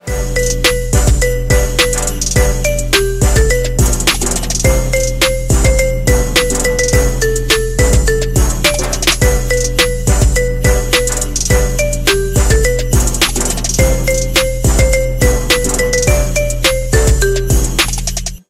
Categoría Festivo